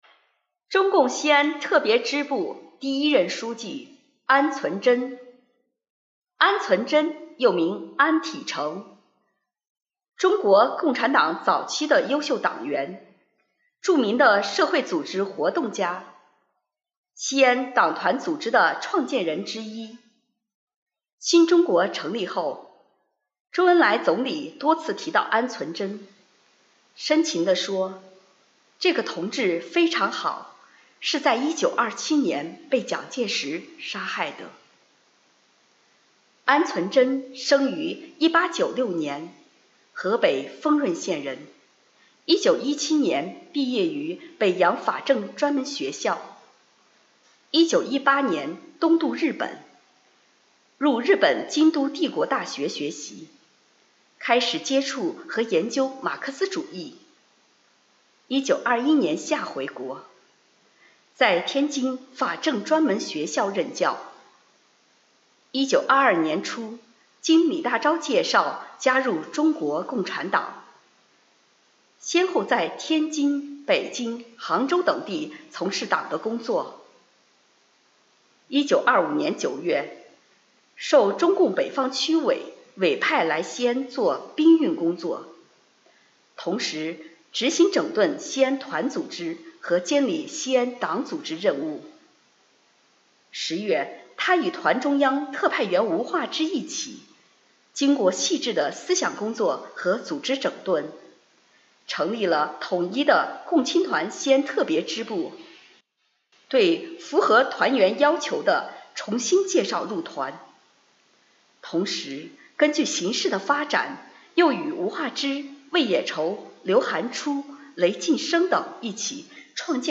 【红色档案诵读展播】西安地区第一个党支部——中共西安特别支部的第一任书记安存真